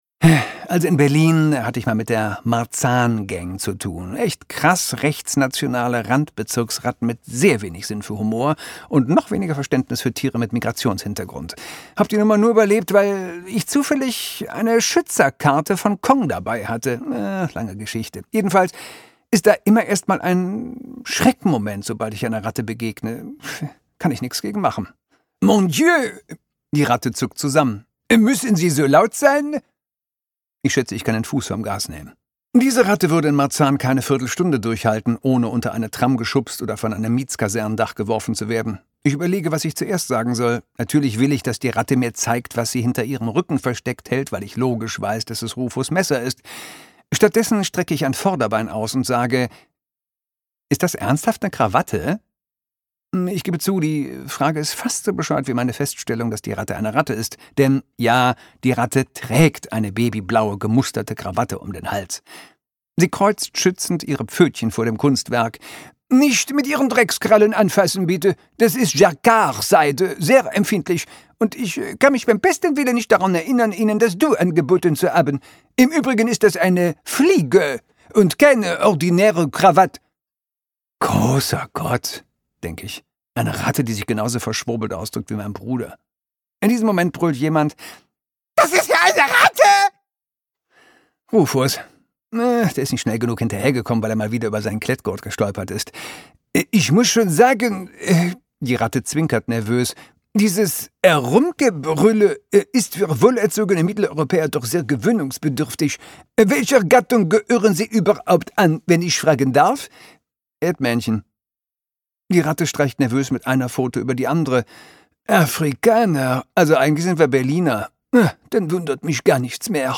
2024 Argon Hörbuch
Sprecher: Christoph Maria Herbst